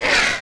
快速拨开2zth070521.wav
通用动作/01人物/02普通动作类/快速拨开2zth070521.wav